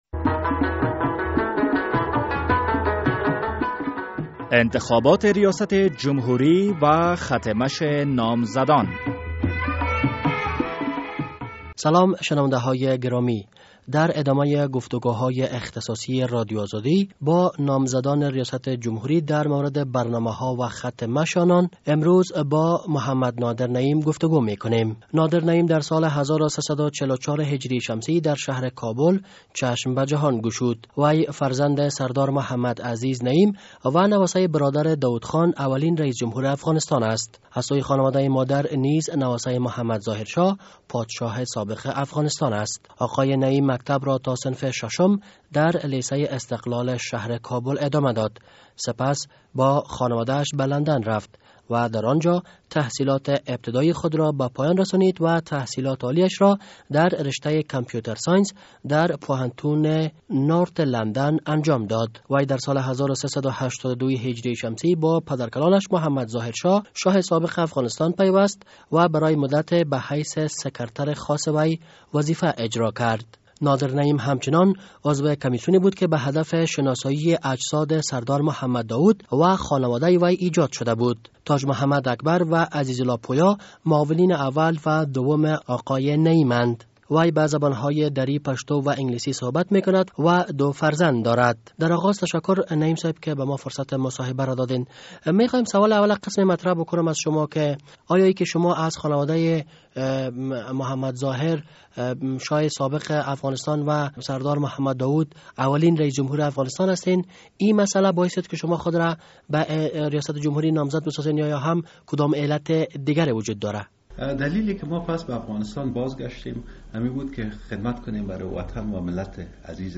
مصاحبهء اختصاصی